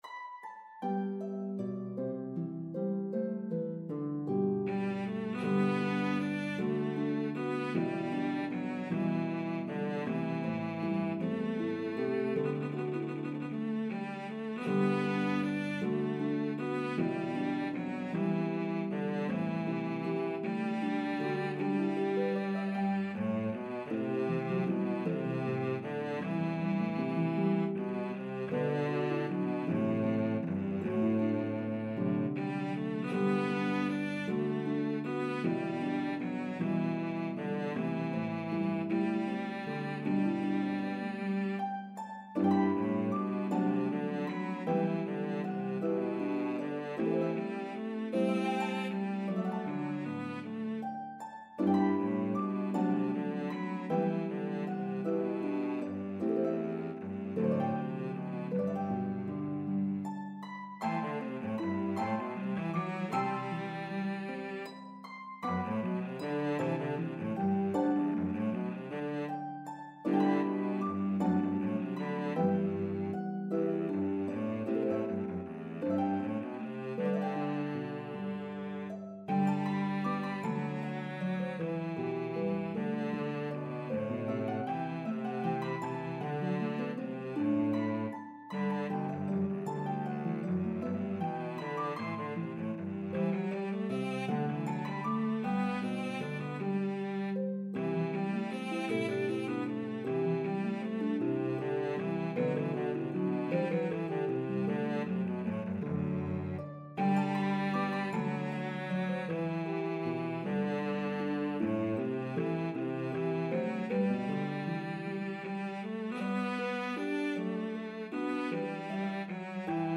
Harp and Cello version